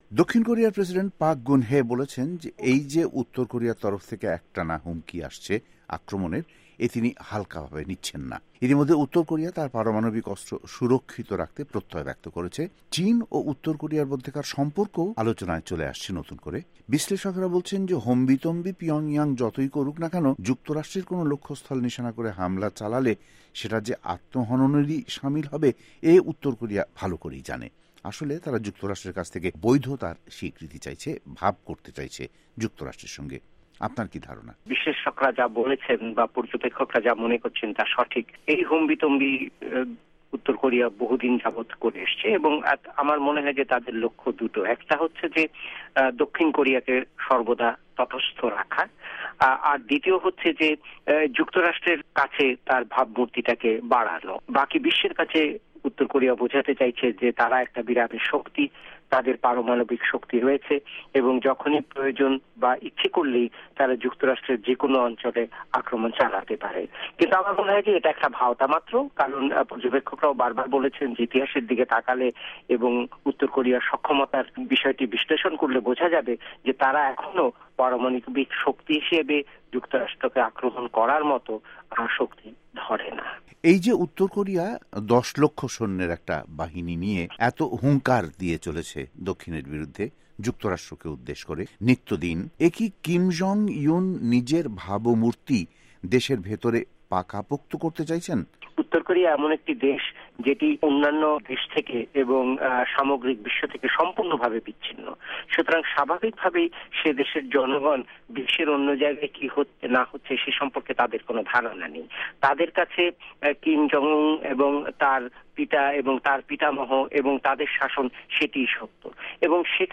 নিউ ইয়র্ক থেকে সংবাদ বিশ্লেষক-ভাস্যকার
ওয়াশিংটন স্টুডিও থেকে তাঁর সঙ্গে কথা বলেন